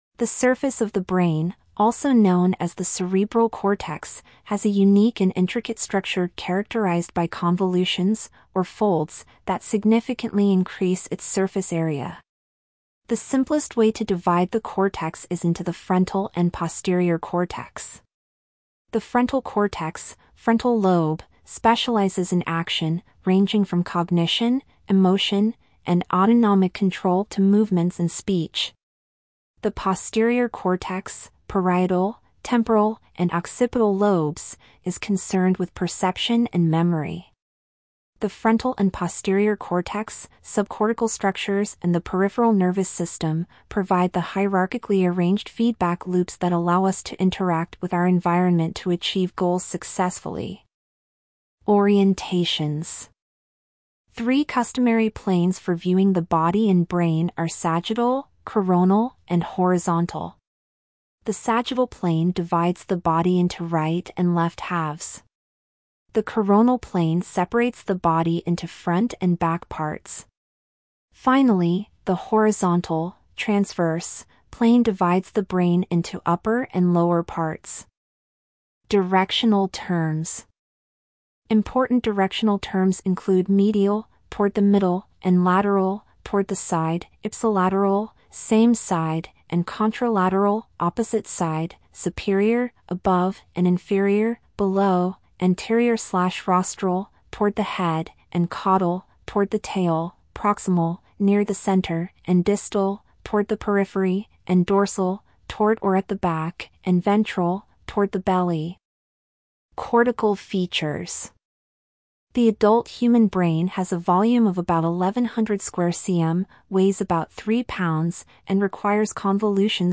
Click on our narrator icon to listen to this post.